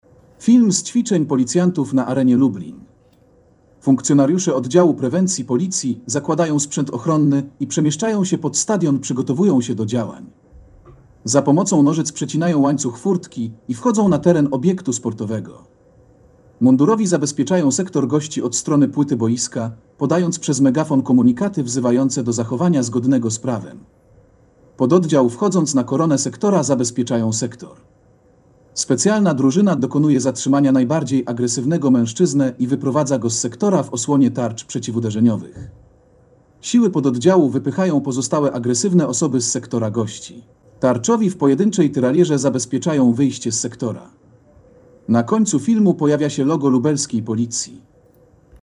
Nagranie audio Audiodeskrypcja filmu ćwiczenia policjantów na stadionie Arena Lublin